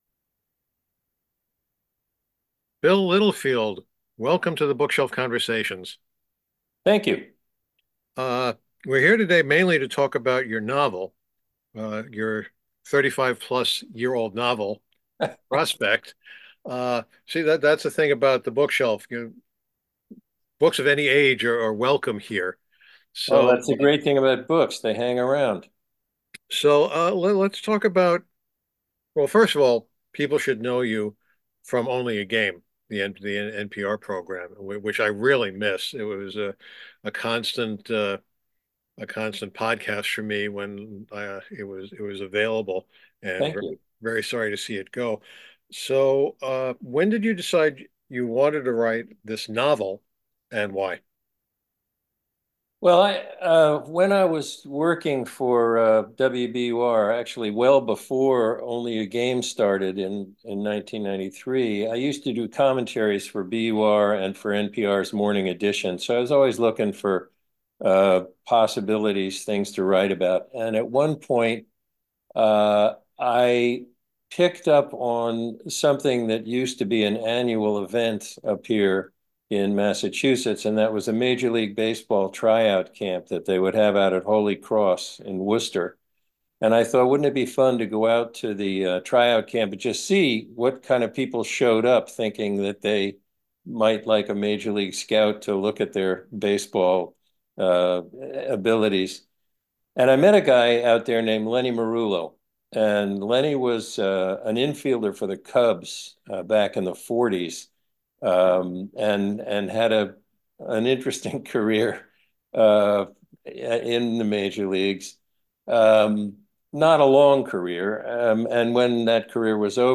There was something about Bill Littlefield’s voice when he read his poetry (or as he refers to it, doggerel) on NPR’s Only a Game that was so soothing, as opposed to those loud-mouthed shouters on various sports talk programs.